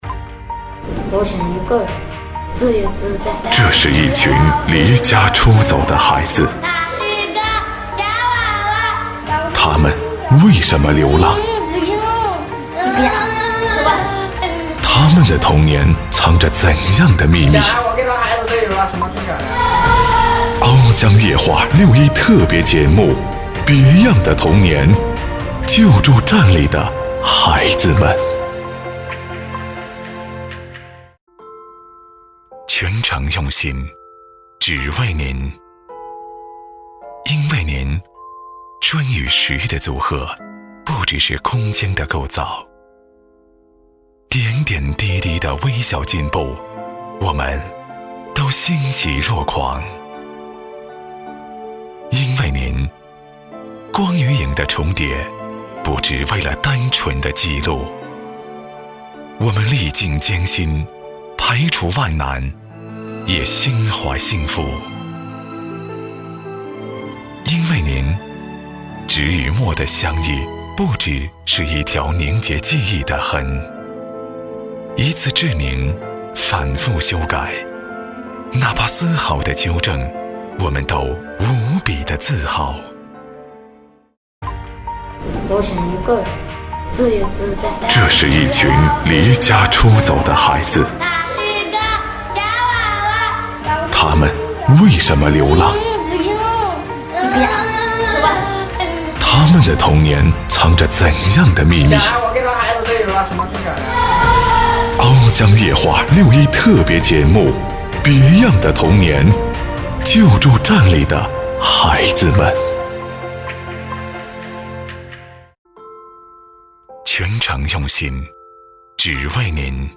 • 男S355 国语 男声 宣传片-全程用心-宣传专题-深沉浑厚 大气浑厚磁性|沉稳|科技感